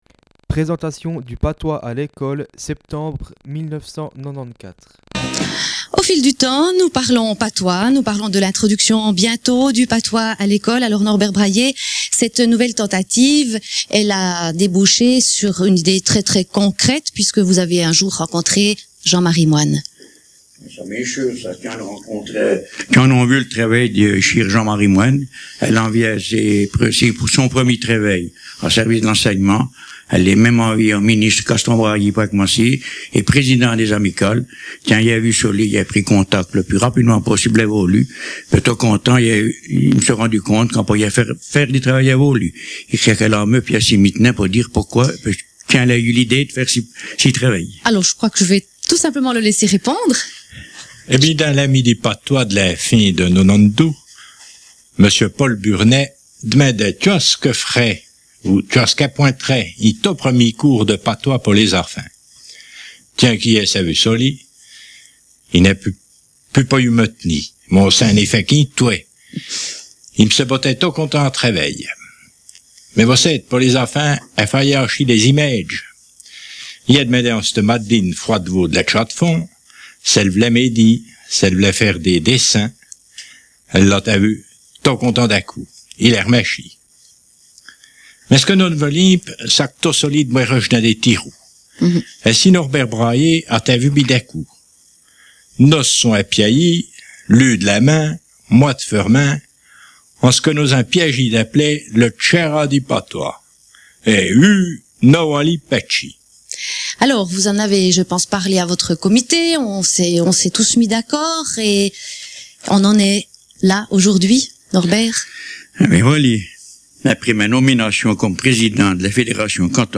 Emission de Fréquence Jura